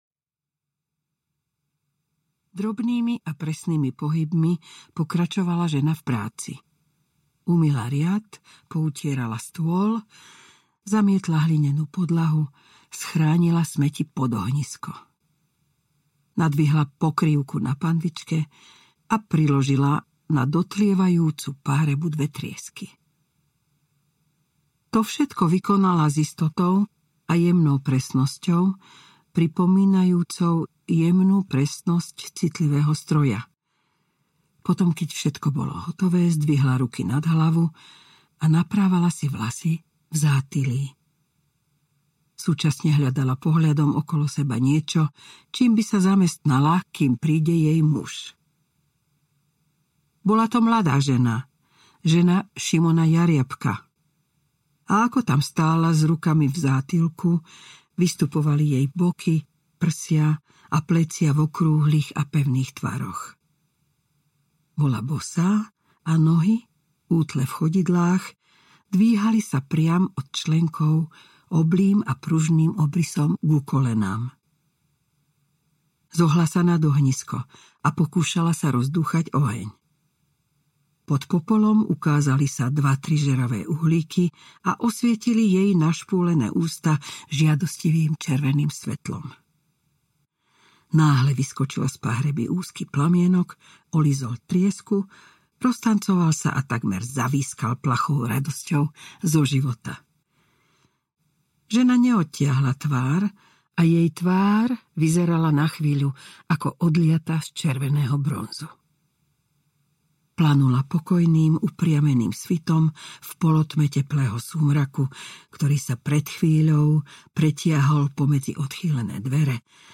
Drak sa vracia audiokniha
Ukázka z knihy
• InterpretEmília Vášáryová